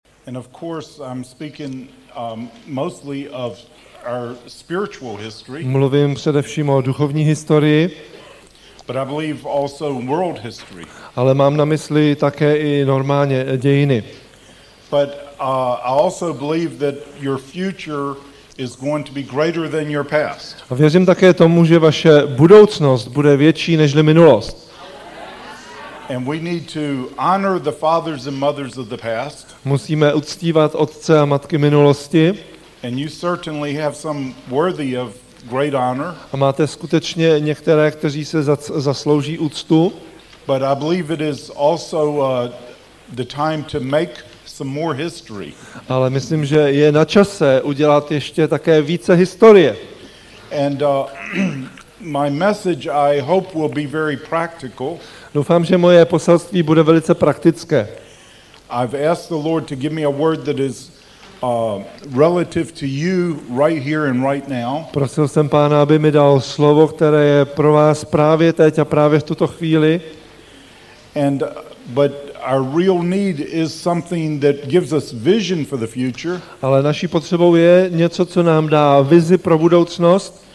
Audiokniha
Na pozvání Křesťanské misijní společnosti měl v roce 2003 v Praze dvoudenní seminář.